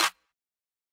Metro Snares [Bout].wav